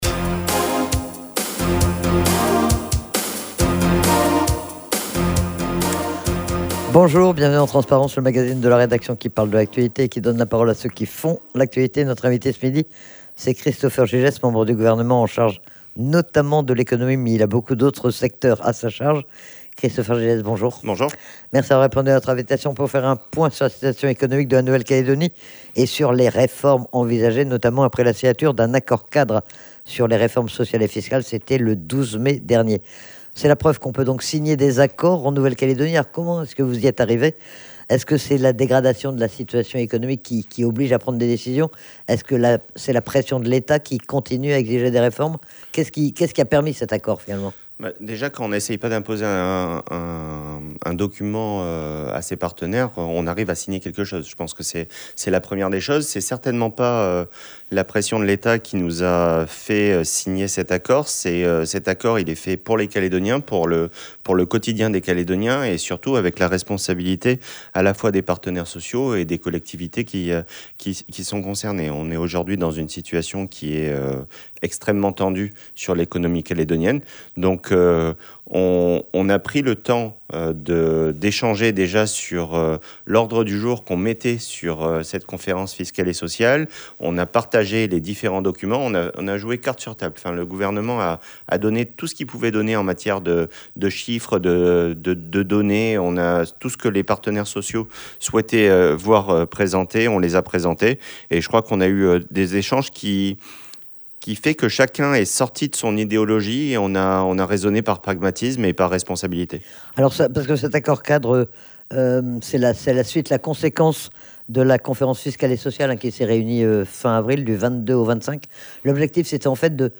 Le membre du gouvernement en charge, notamment de l'économie était interrogé sur la récente conférence sociale et fiscale et sur les suites qui vont lui être données, mais aussi sur la situation socio-économique de la Nouvelle-Calédonie et sur l'avenir institutionnel.